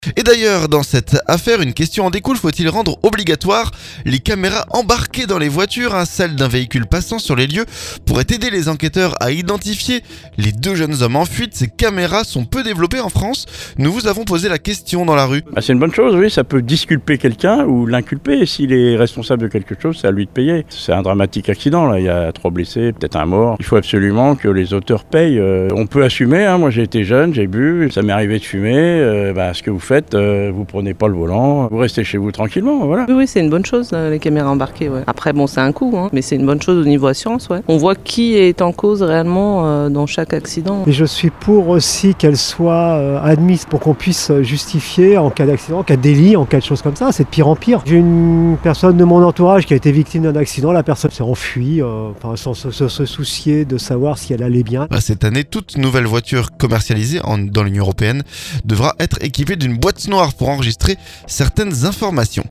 Nous avons posé la question dans les rues.